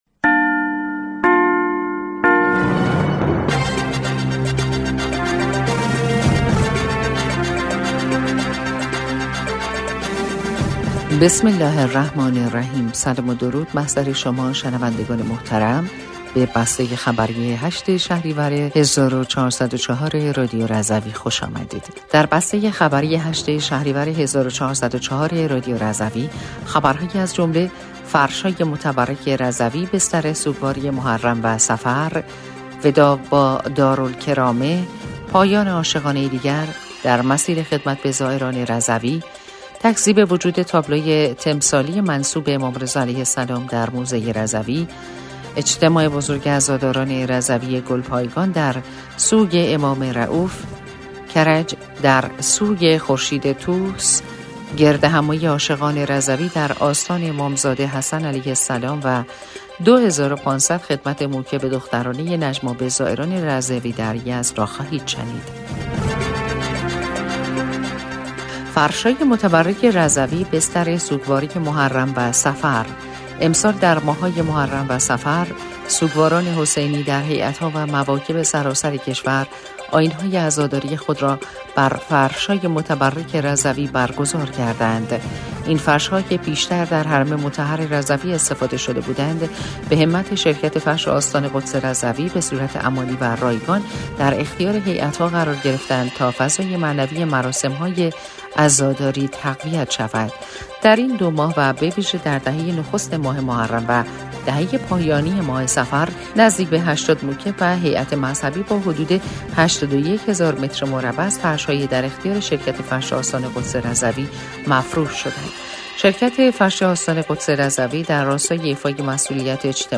بسته خبری ۸ شهریور ۱۴۰۴ رادیو رضوی/